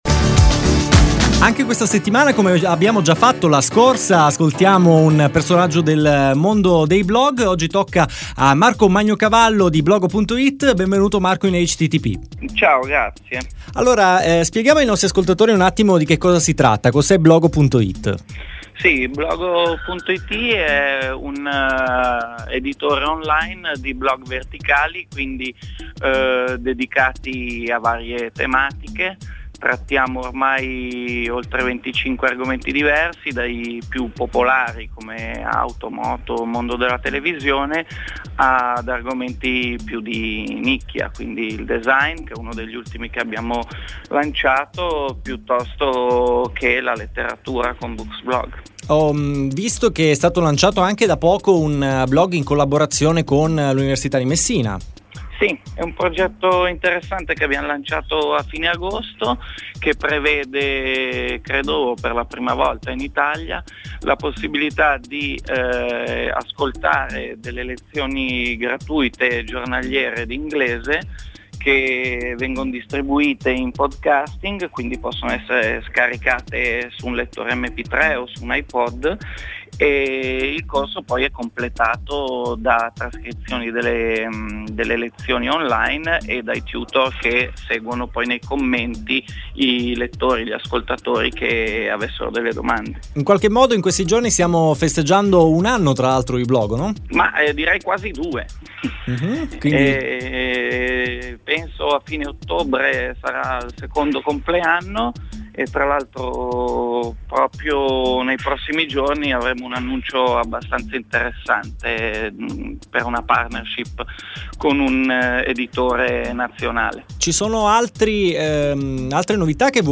Intervista citata